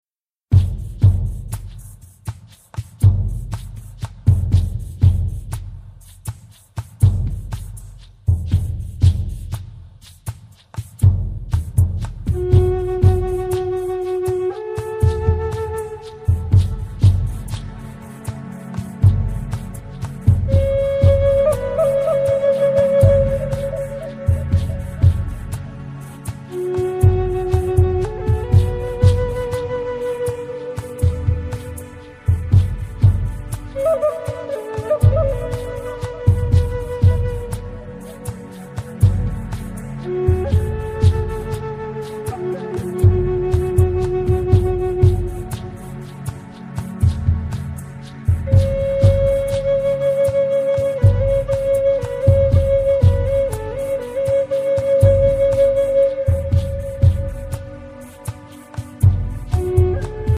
Native American Flute & Folk Music